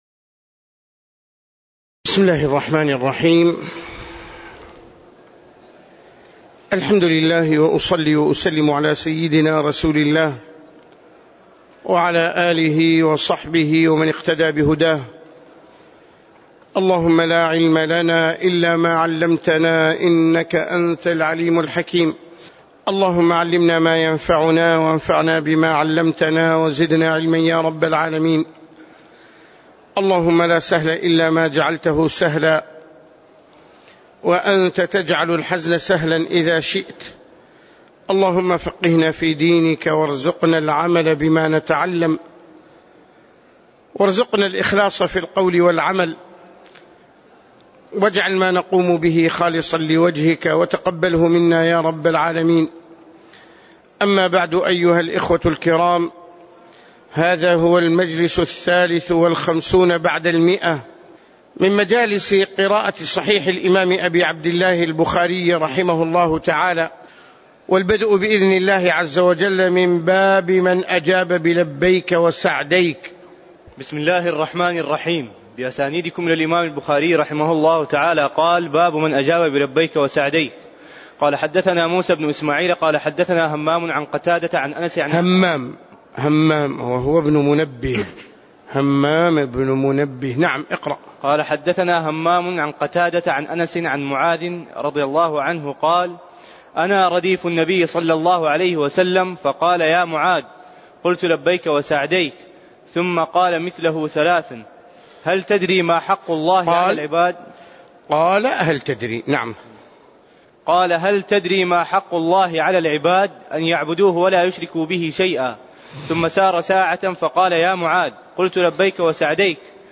تاريخ النشر ٢٥ محرم ١٤٣٩ هـ المكان: المسجد النبوي الشيخ